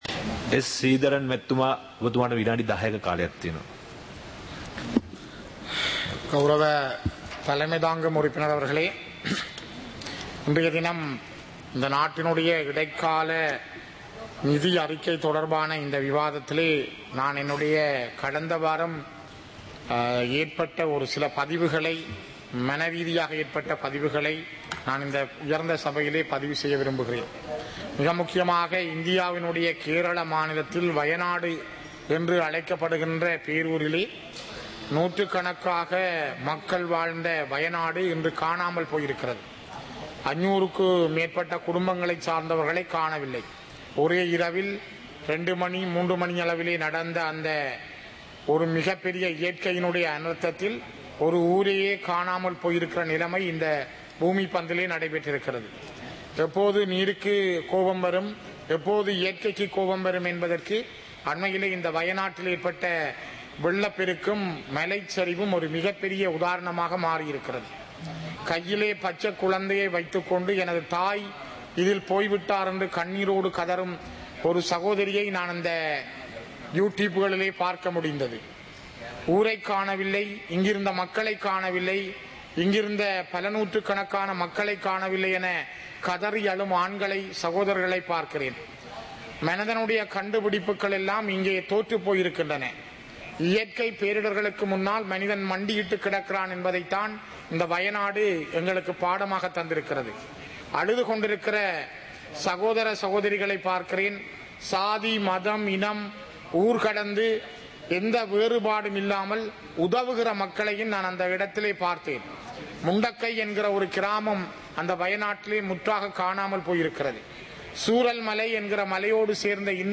சபை நடவடிக்கைமுறை (2024-08-07)
பாராளுமன்ற நடப்பு - பதிவுருத்தப்பட்ட